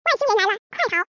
步驟2：接著在方框內輸入文字，按一下播放按鈕，成功將文字轉語音！